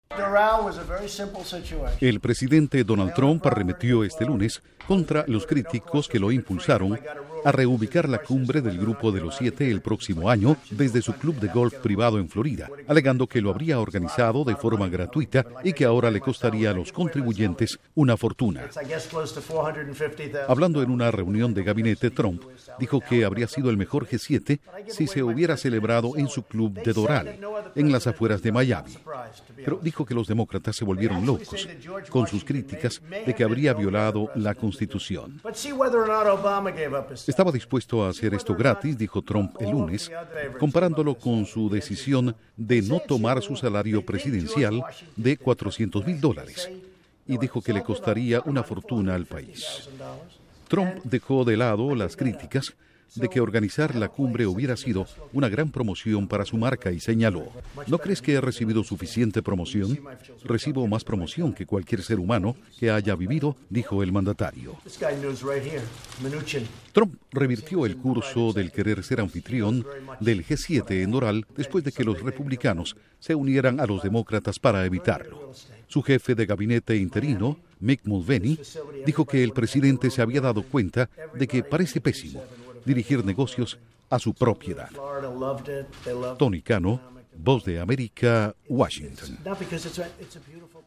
Duración: 1:30 Con declaraciones de Trump